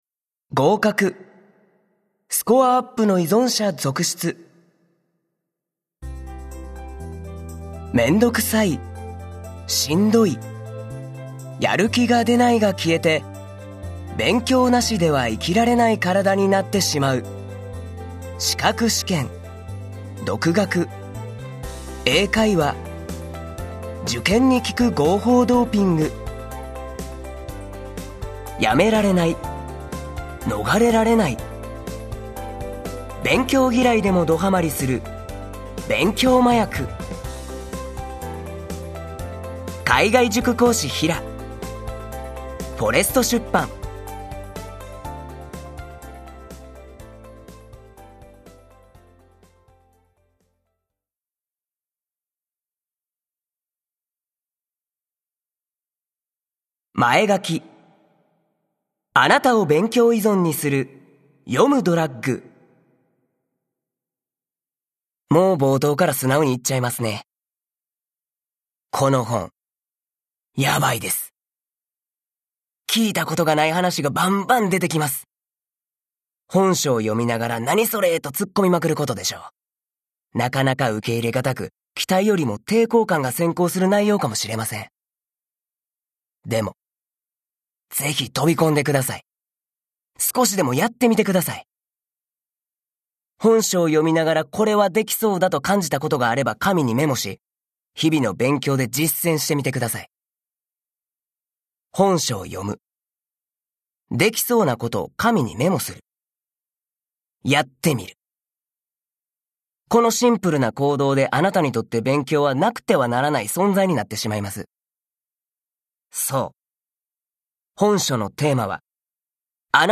[オーディオブック] 勉強嫌いでもドハマりする 勉強麻薬